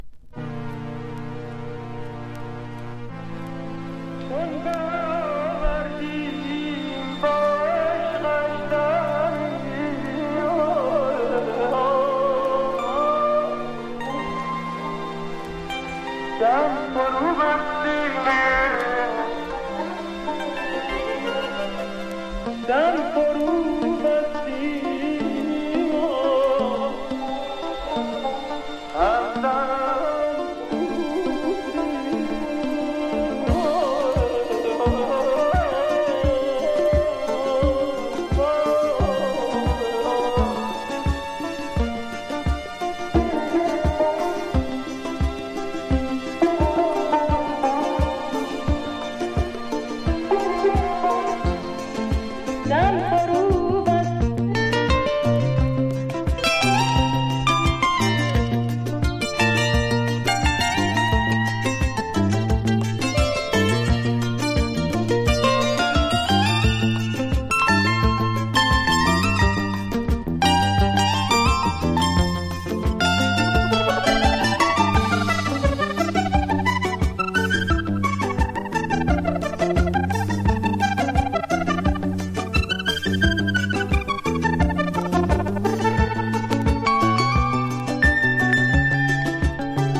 • 盤面 : EX+ (美品) キズやダメージが無く音質も良好